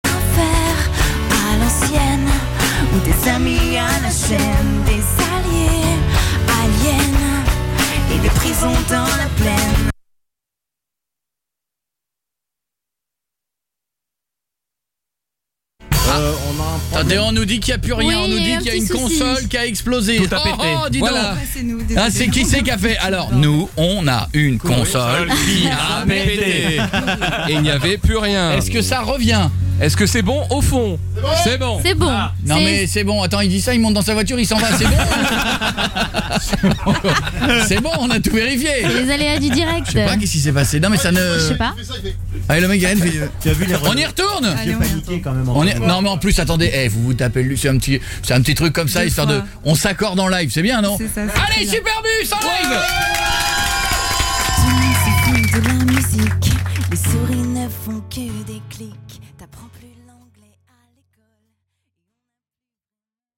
Une console a fait des siennes pendant le live de Superbus.